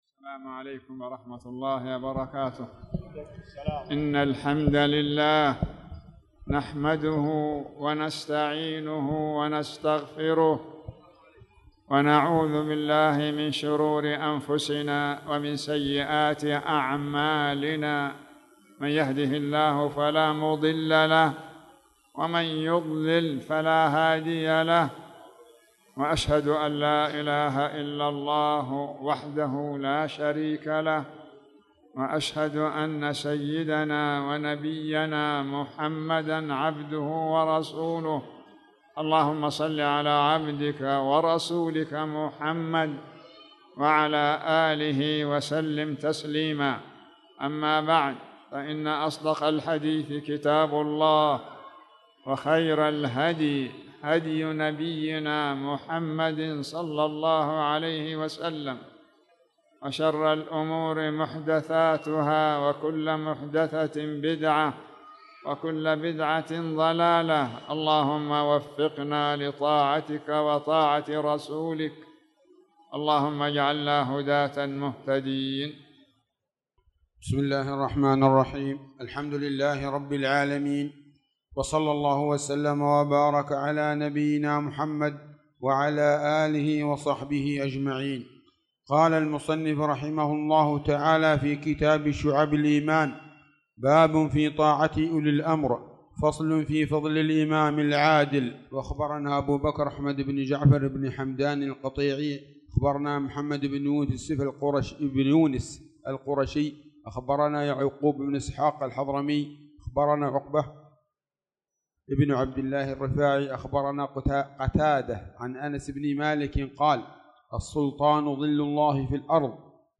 تاريخ النشر ١٤ شوال ١٤٣٧ هـ المكان: المسجد الحرام الشيخ